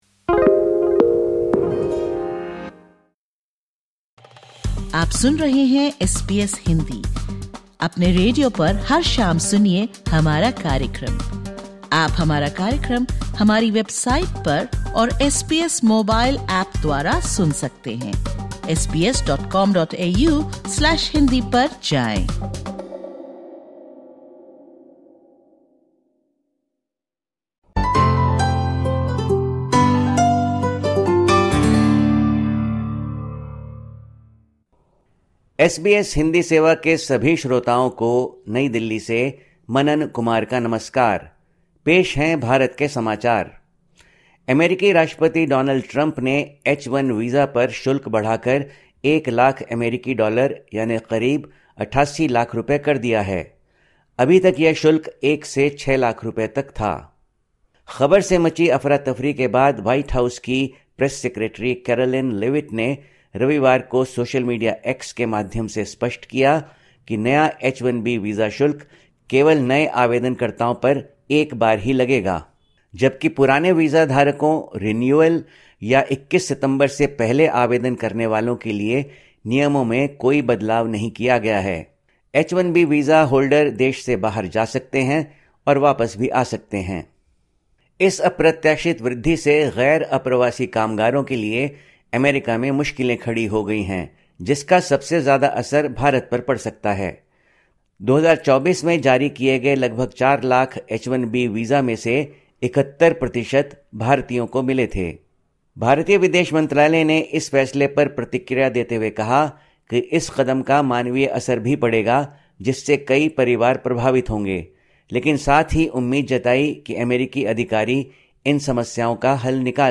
Listen to the latest SBS Hindi news from India. 22/09/2025